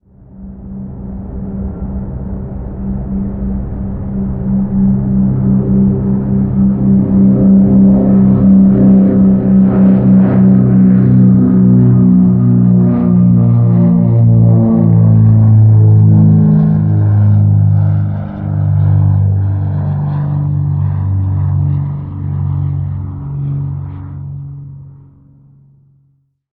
airplane-sound-effect